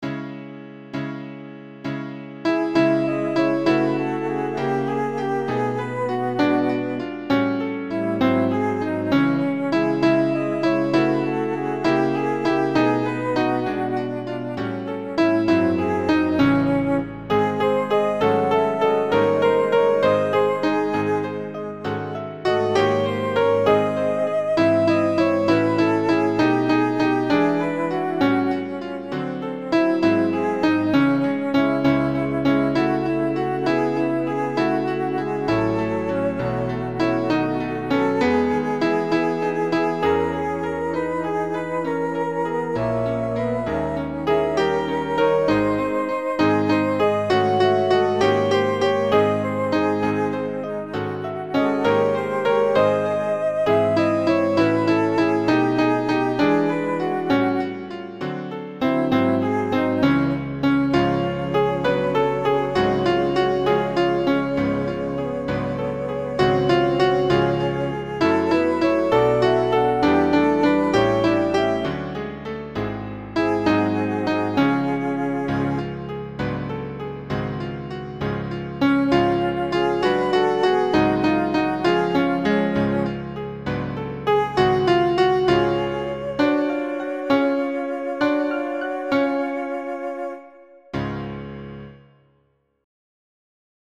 alto
Au-bord-de-leau-Faure-alto.mp3